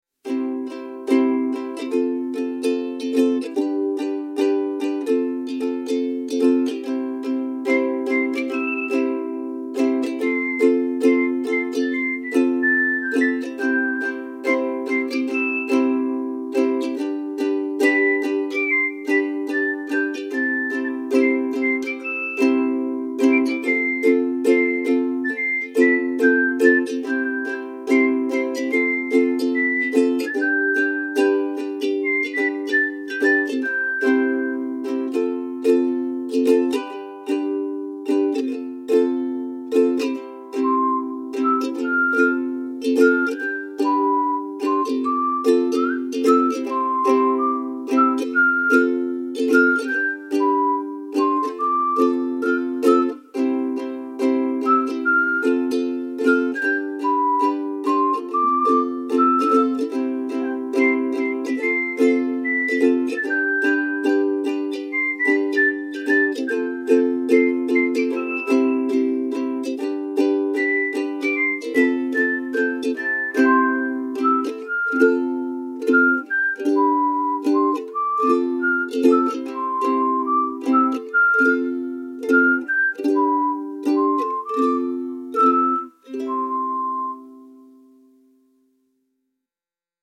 simple ukulele and whistling melody with a warm home-video feel